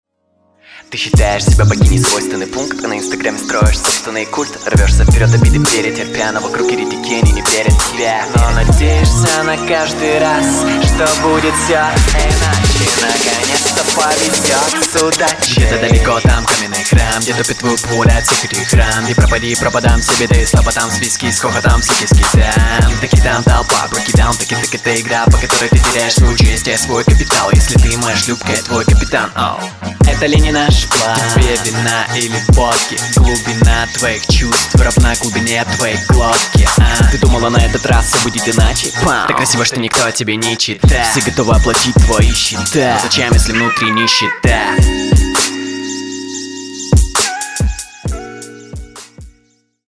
Неприметный трек. Как фон. Вроде играет, слушаешь, а эмоций вообще не вызывает. Тараторишь нормально, но флоу вяленький. Возможно бит подпортил все. Не могу плюс поставить.
Вокал тебе не дается. Слетаешь с бита, интонируешь очень скромно и сдержанно